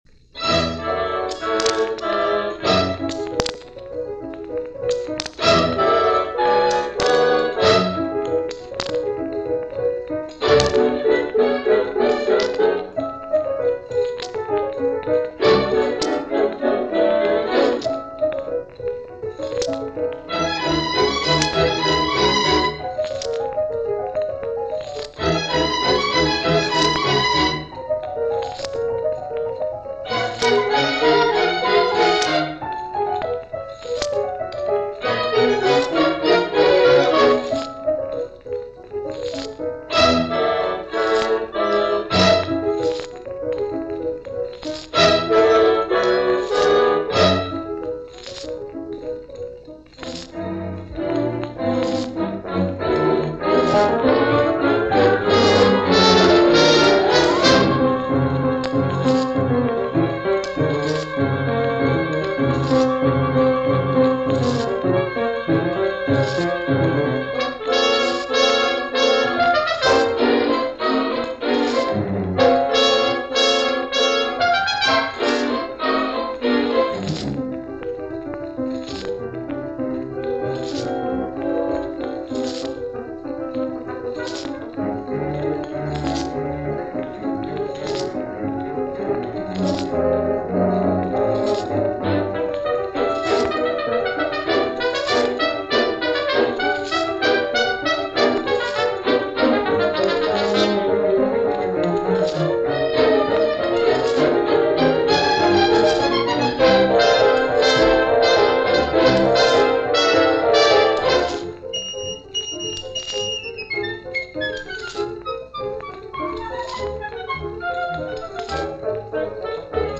from a 1949 Radio broadcast
Concertino for Piano and Orchestra
French orchestras just sounded different, with a richness of playing and phrase few orchestras could match during this time.
Needless to say, it has a lot of scratches going into it.